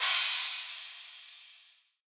MZ Crash [Gorgeous].wav